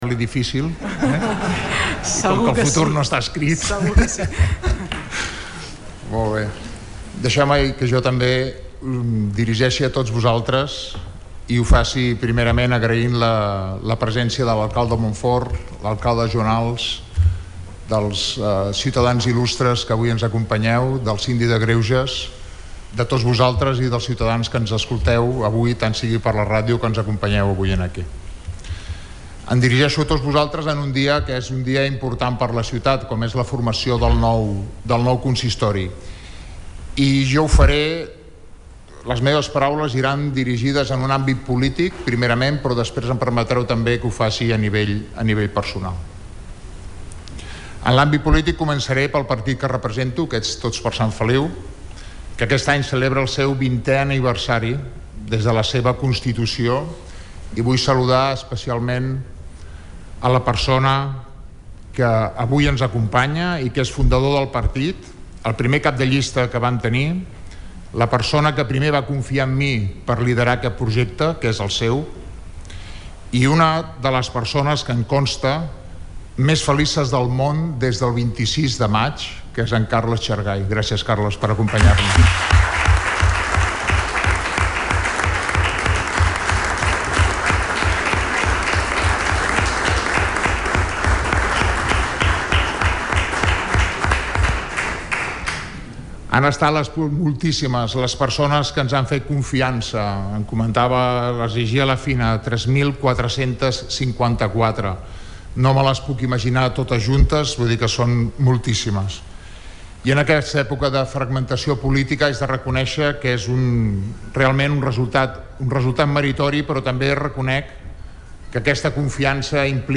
Una vegada s’ha conegut el resultat de la votació hi ha hagut aplaudiments d’enhorabona al nou alcalde de Sant Feliu de Guíxols.
Durant el discurs d’investidura ha destacat els diferents obstacles que hi ha hagut fins que han arribat a la situació actual.